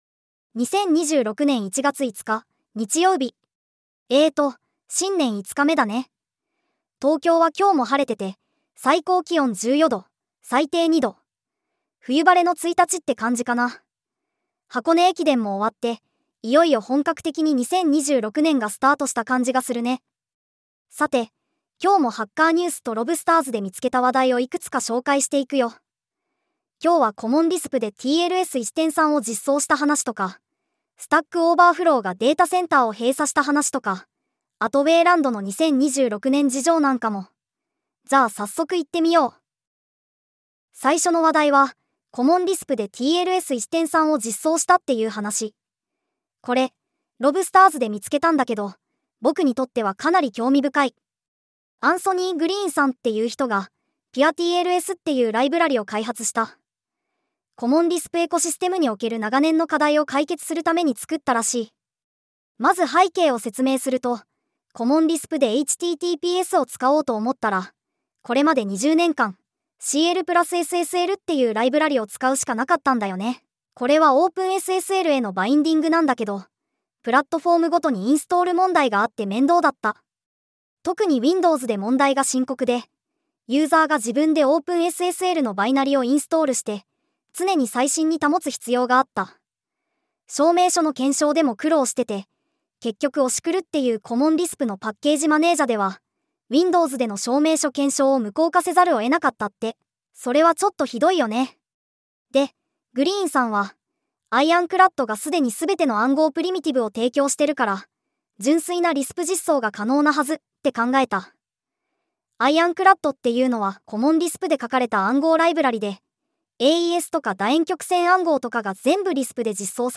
テトさんに技術系ポッドキャストを読んでもらうだけ